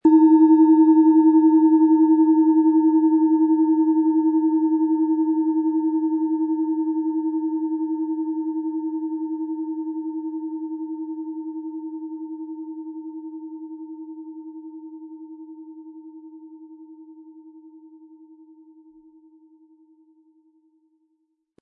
Im Sound-Player - Jetzt reinhören können Sie den Original-Ton genau dieser Schale anhören.
Sanftes Anspielen mit dem gratis Klöppel zaubert aus Ihrer Schale berührende Klänge.
SchalenformBihar
MaterialBronze